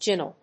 /ˈɡɪnə̆l(米国英語)/